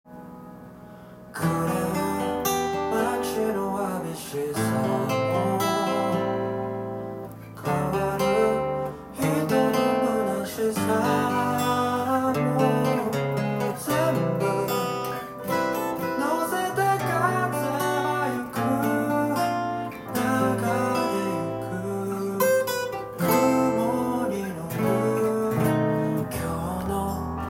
アルペジオ練習に最適です。
ジャズっぽくかなりおしゃれな曲ですが癒し効果　抜群です！
音源にあわせてギターで譜面通り弾いてみました
主にギターは、アルペジオするパターンのtab譜を作ってみました。
コードは、ほぼハイポジションのバレーコードが多くなっています。